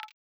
hover.ogg